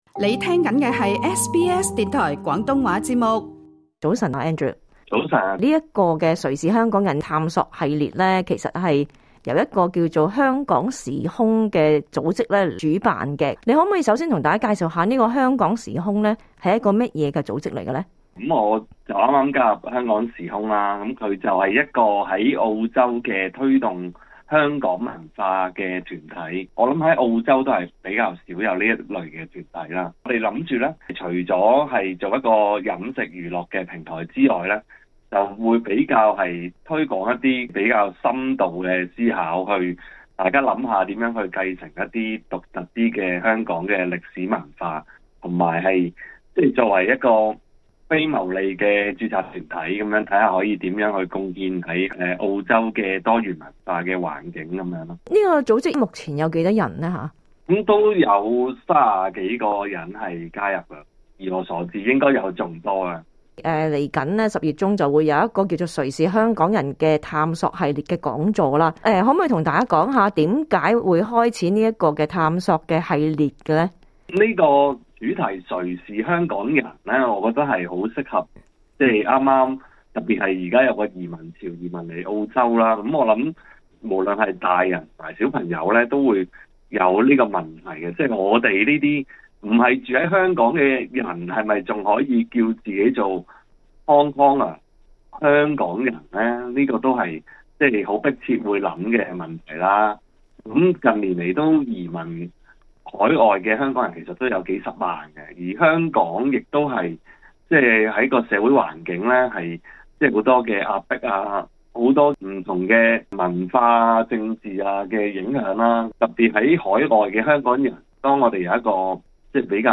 想了解這個「我手寫我口」的運動，請大家收聽這節訪問。